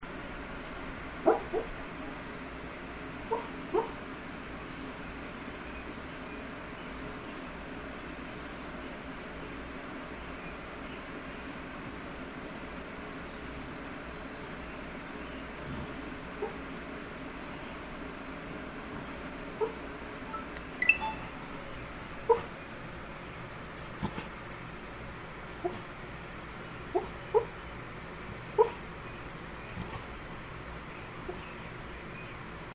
I finally managed to get athena sleep barking. at least that is what I call it, even though they don't sound like real barks. this is a very short recording, but when she started doing it, I just happened to be sitting at my desk, and got out the digital recorder, and turned it on. other times I tried that, it woke her up, but not this time!